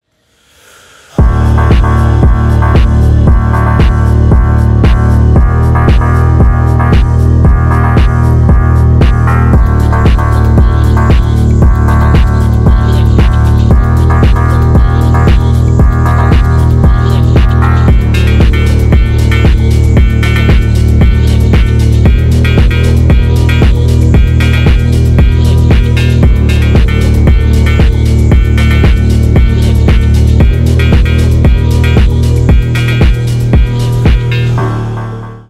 Рэп и Хип Хоп
громкие # без слов